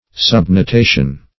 Search Result for " subnotation" : The Collaborative International Dictionary of English v.0.48: Subnotation \Sub`no*ta"tion\, n. [L. subnotatio a signing underneath, fr. subnotare to subscribe; sub under + notare to note or mark.]